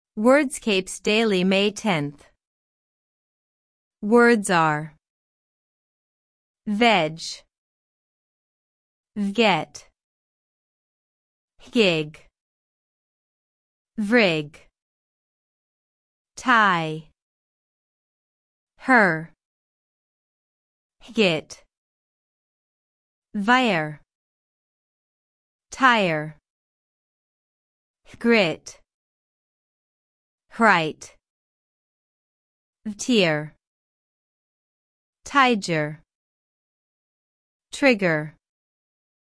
On this page you’ll get the full Wordscapes Daily Puzzle for May 10 Answers placed in the crossword, all bonus words you can collect along the way, and an audio walkthrough that can read the answers to you at the speed you like while you’re still playing.